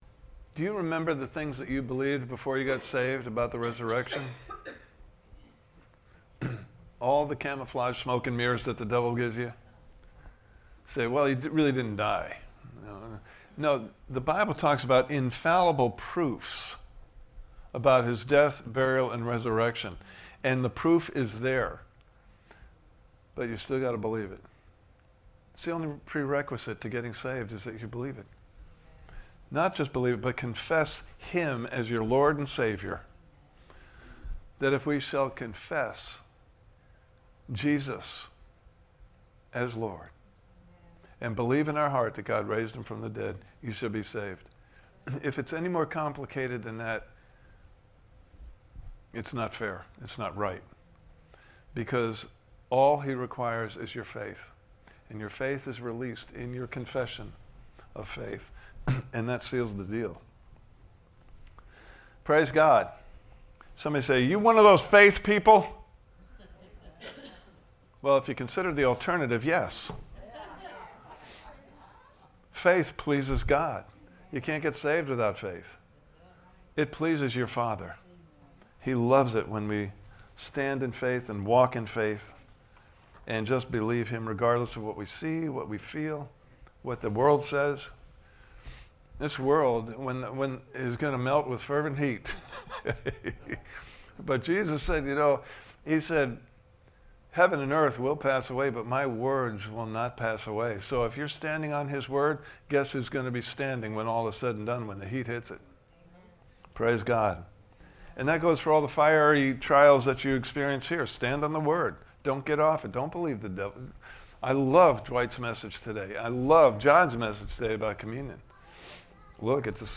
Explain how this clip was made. Living a Redeemed Life Service Type: Sunday Morning Service « Part 4